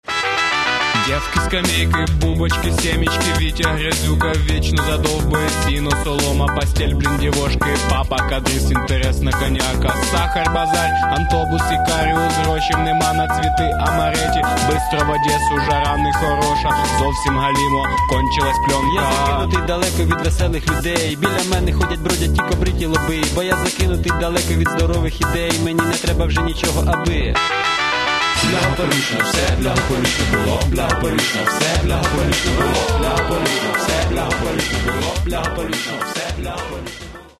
Каталог -> Рок и альтернатива -> Регги